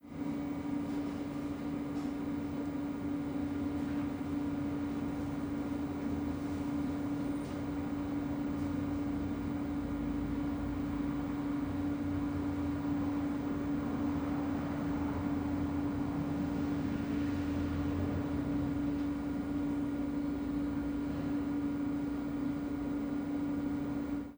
Ambiente interior de una nave con maquinaria ventilando
ventilador
maquinaria
Sonidos: Industria